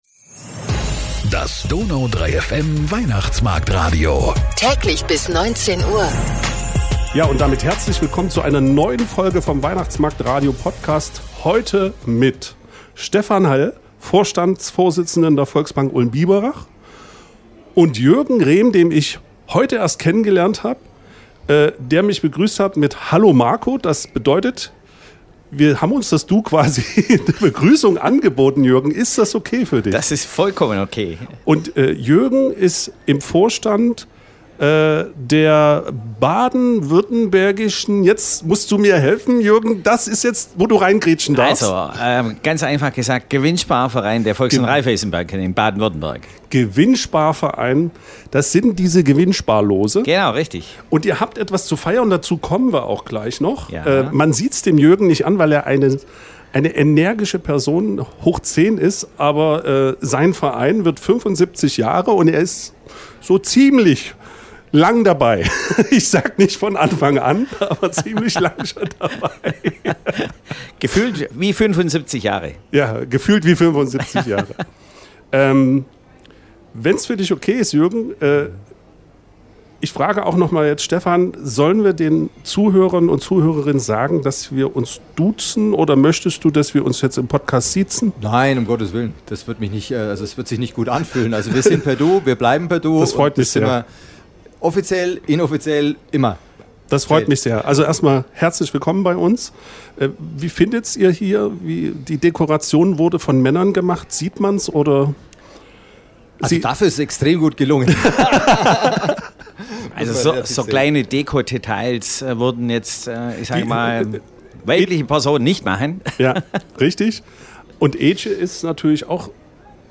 Viele Fragen und 2 kompetente und unterhaltsame Gäste, die sie alle beantworten